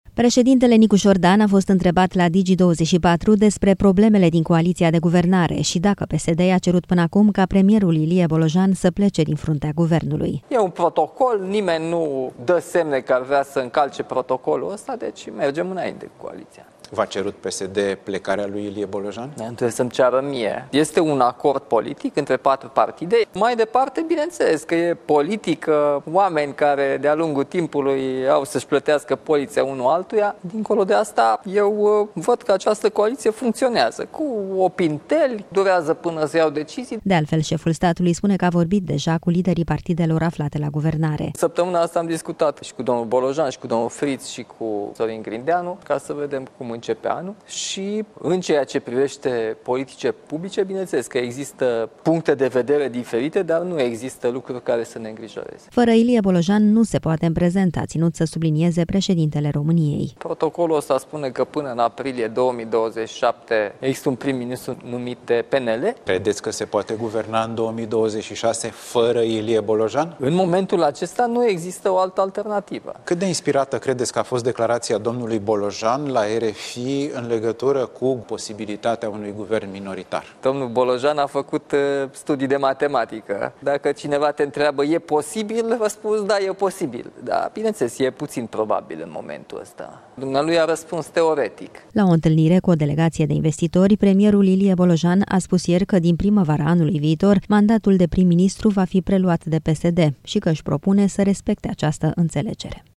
Președintele Nicușor Dan a fost întrebat la Digi 24 despre problemele din coaliția de guvernare și dacă PSD i-a cerut până acum ca premierul Ilie Bolojan să plece din fruntea Guvernului.